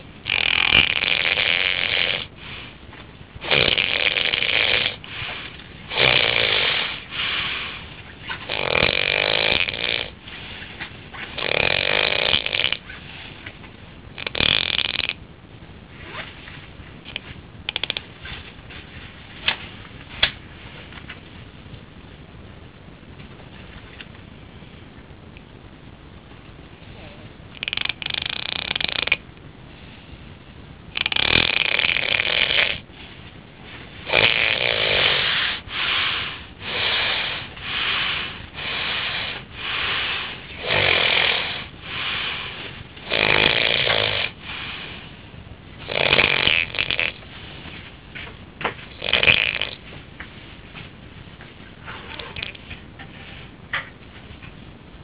snore.au